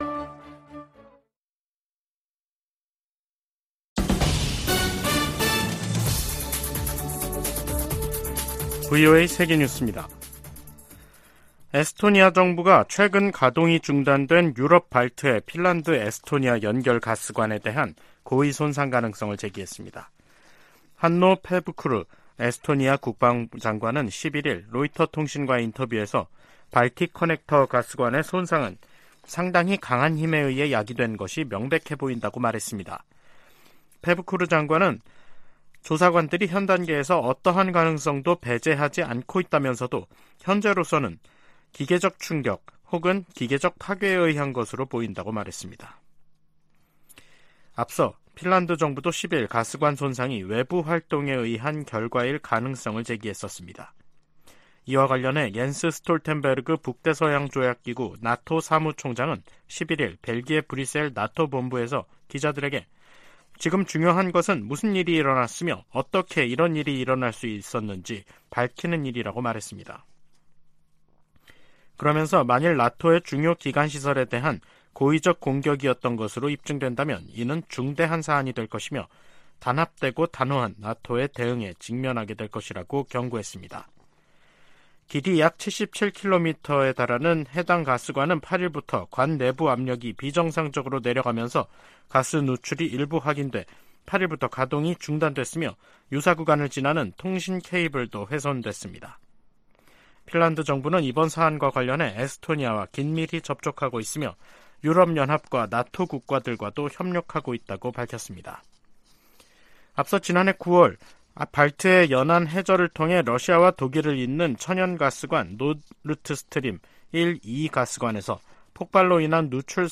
VOA 한국어 간판 뉴스 프로그램 '뉴스 투데이', 2023년 10월 11일 3부 방송입니다. 조 바이든 미국 대통령이 이스라엘에 대한 하마스의 공격을 테러로 규정하고 이스라엘에 전폭적 지원을 약속했습니다. 한국 군 당국이 하마스의 이스라엘 공격 방식과 유사한 북한의 대남 공격 가능성에 대비하고 있다고 밝혔습니다. 미 국무부가 하마스와 북한 간 무기 거래 가능성과 관련해 어떤 나라도 하마스를 지원해선 안 된다고 강조했습니다.